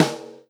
S_snare2_8.wav